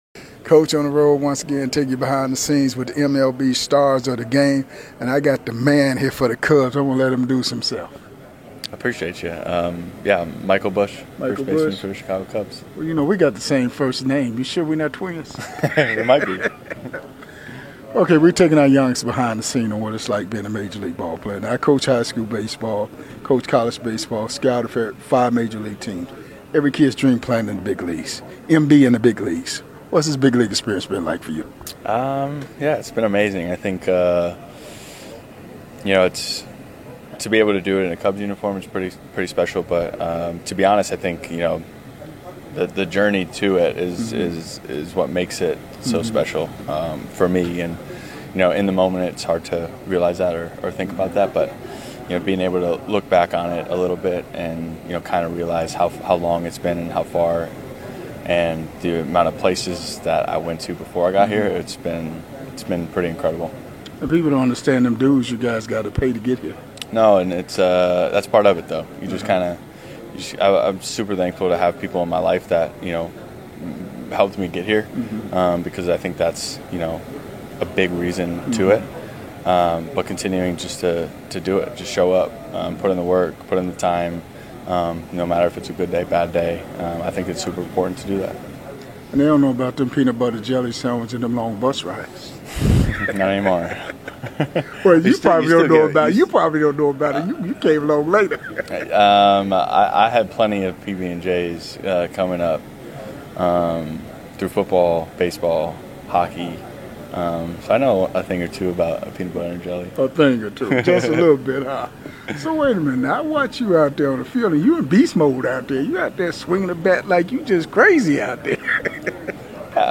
This episode was recorded live at the 2025 HBCU Swingman Classic.